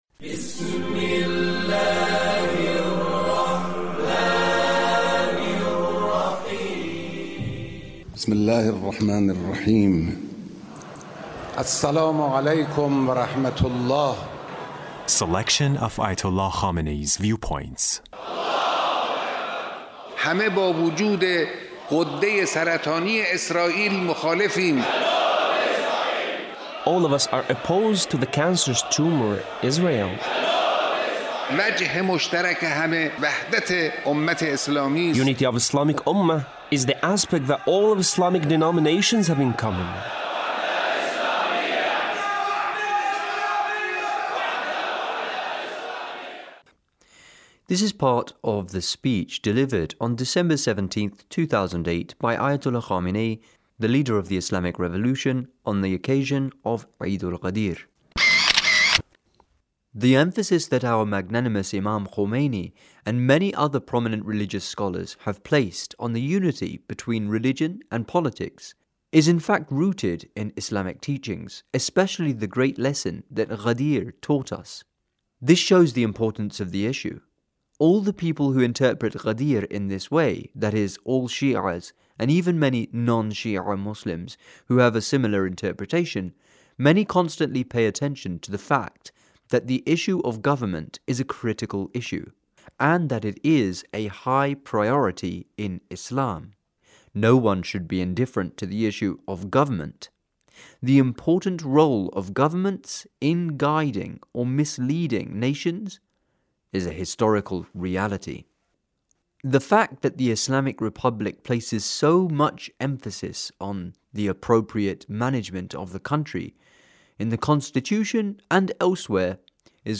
The Leader's speech on the occasion of Eidul-Ghadir.